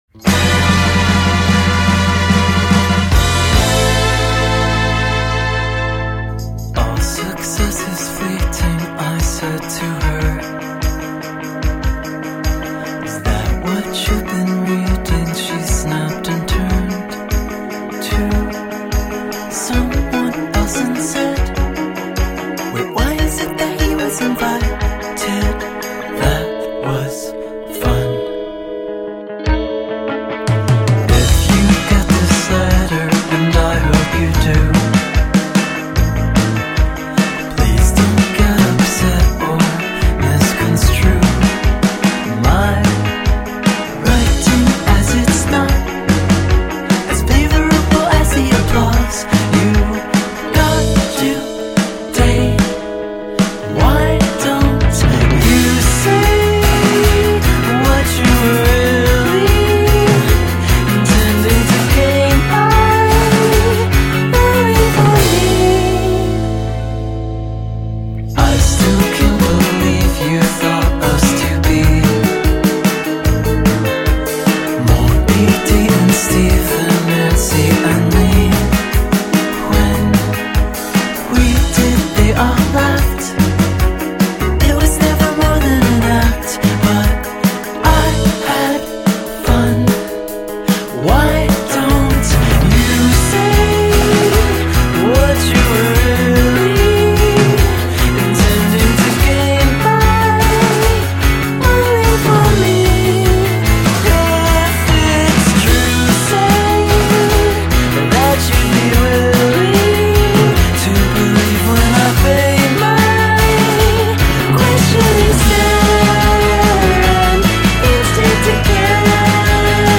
sanno regalare un bel rock sentimentale tutto da ballare